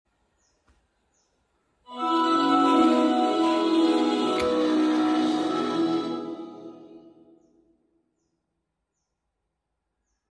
Windows Media Center Opening sound sound effects free download